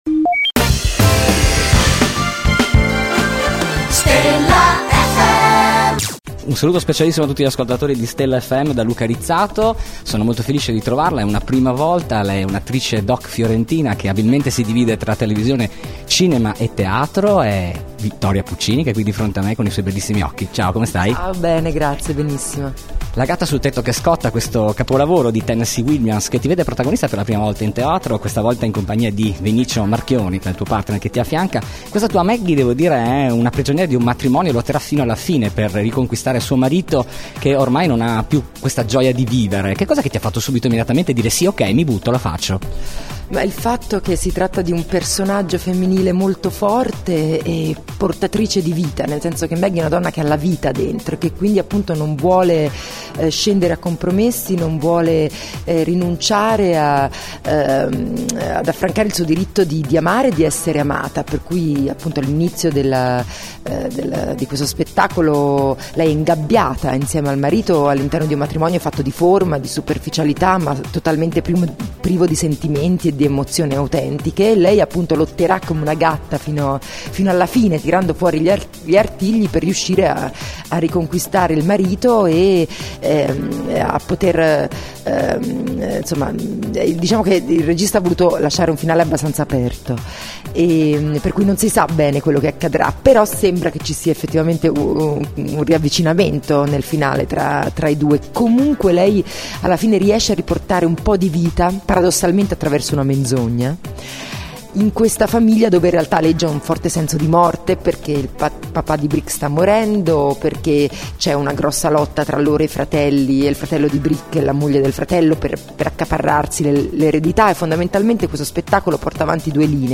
Intervista Vittoria Puccini | Stella FM
Intervista-Vittoria-Puccini.mp3